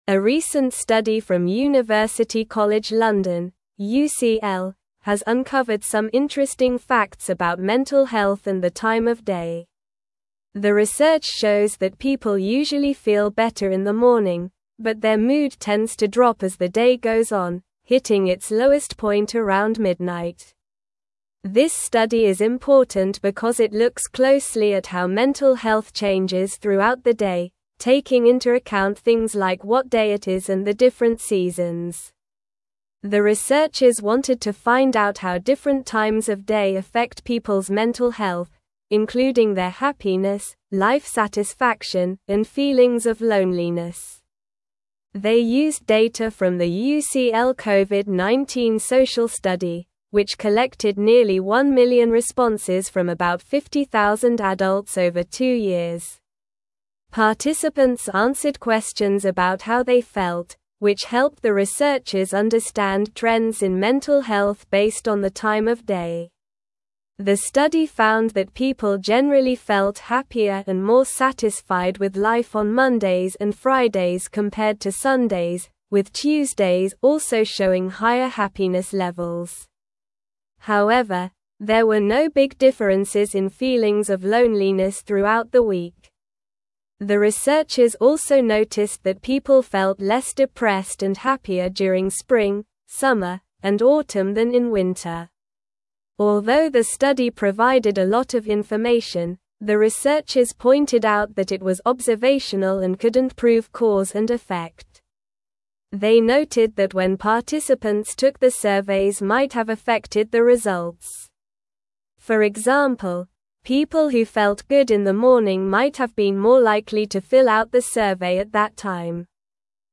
Slow
English-Newsroom-Upper-Intermediate-SLOW-Reading-Mental-Health-Declines-Throughout-the-Day-Study-Finds.mp3